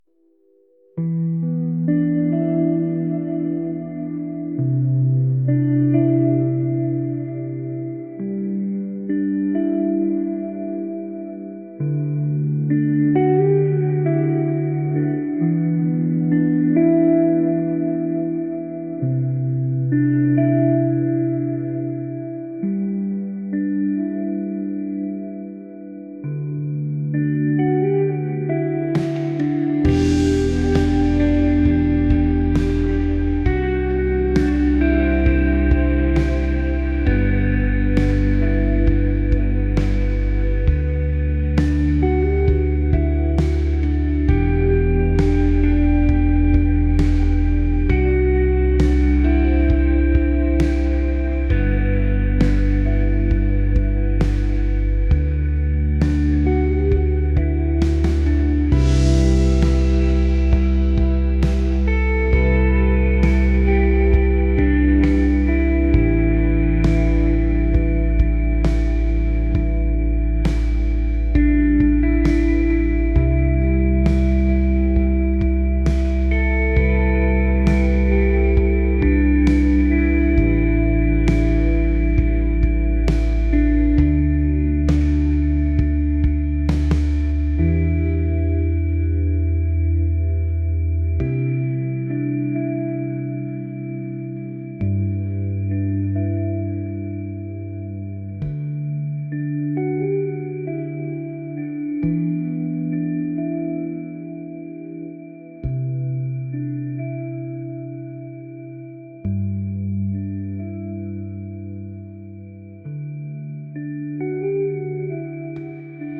atmospheric | pop | ethereal